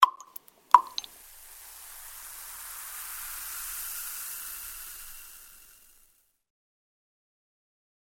Больница звуки скачать, слушать онлайн ✔в хорошем качестве